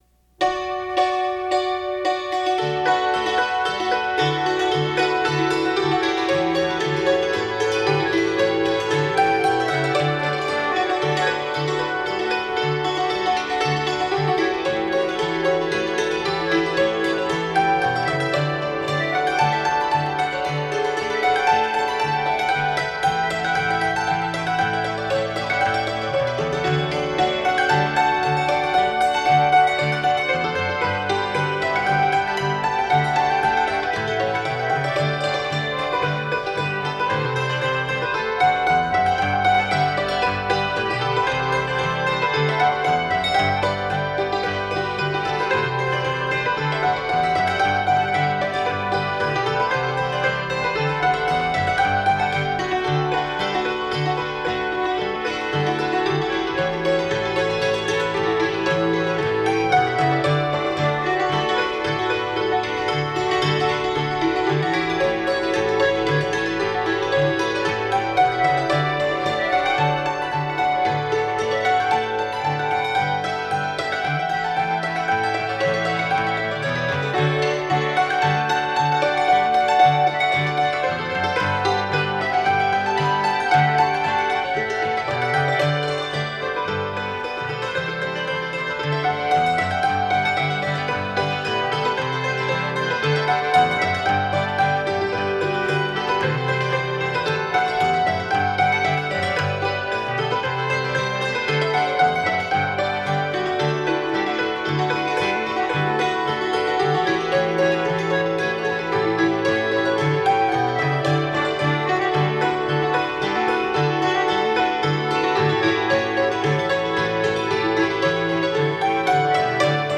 It was recorded in what was for many years the fair office.